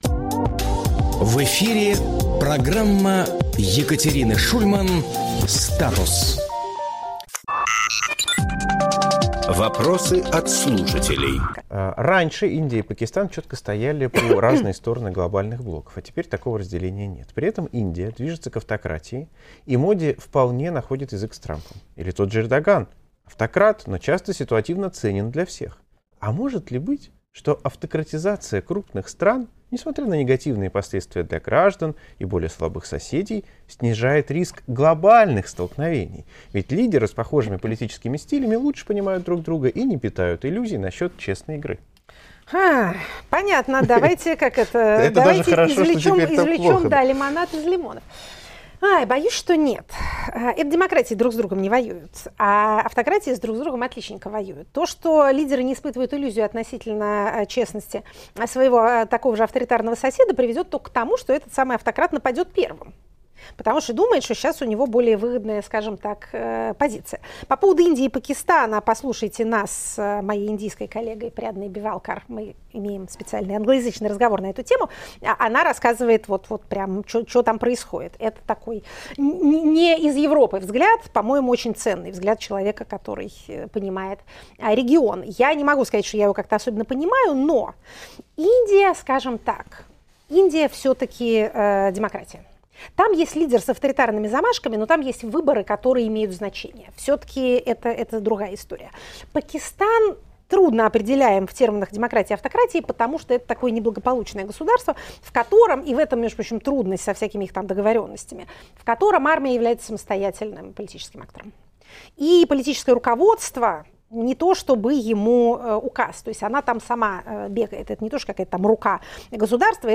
Екатерина Шульманполитолог
Фрагмент эфира от 13.05.25